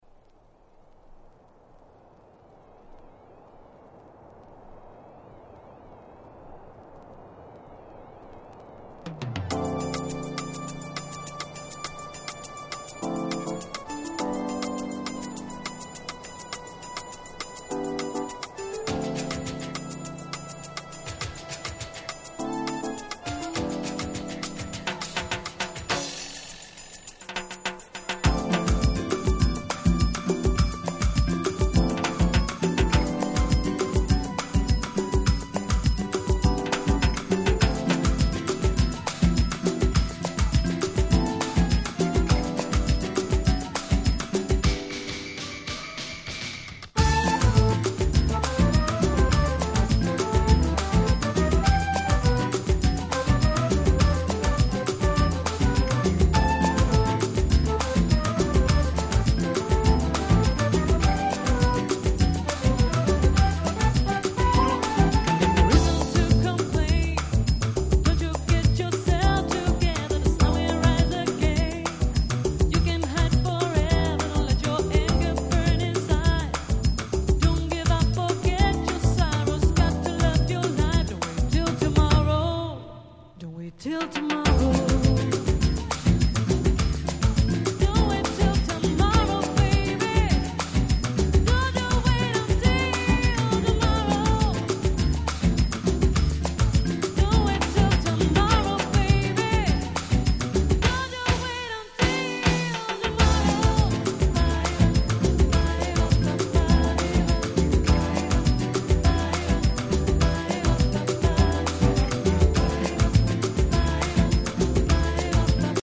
【Media】Vinyl 12'' Single
Brazil/Acid Jazz名盤。